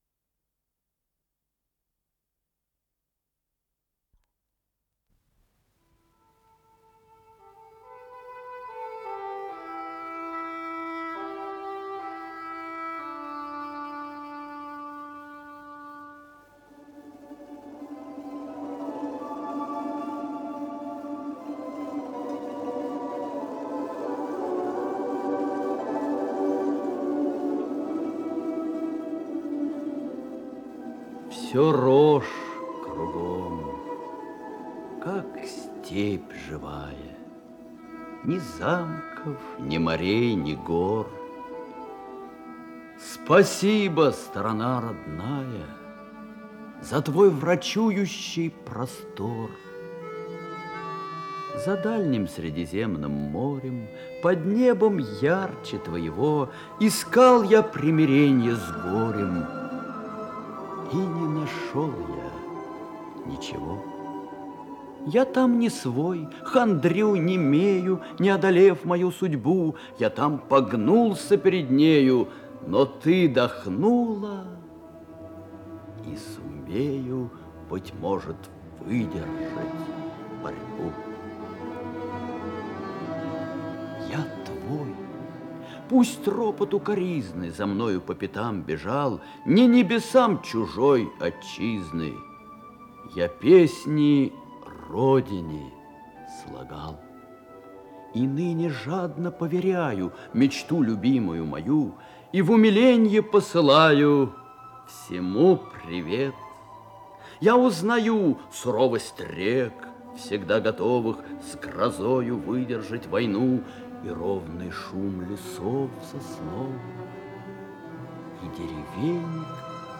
Радиокомпозиция